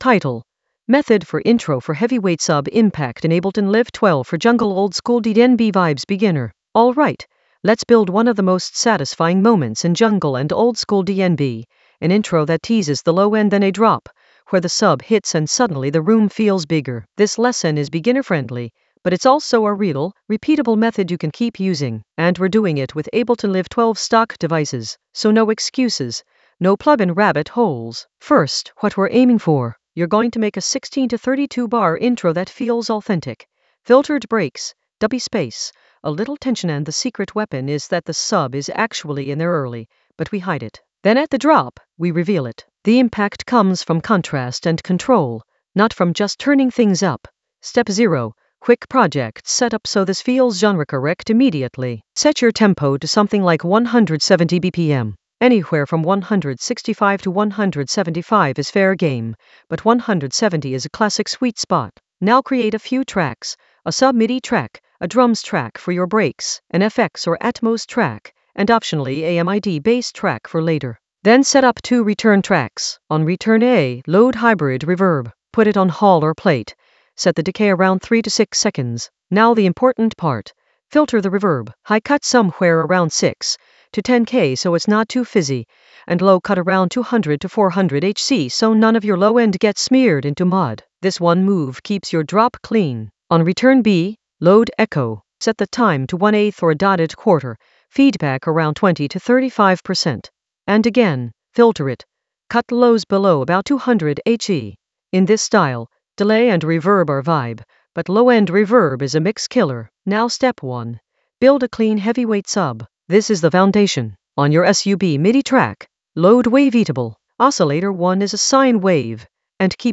Narrated lesson audio
The voice track includes the tutorial plus extra teacher commentary.
An AI-generated beginner Ableton lesson focused on Method for intro for heavyweight sub impact in Ableton Live 12 for jungle oldskool DnB vibes in the Basslines area of drum and bass production.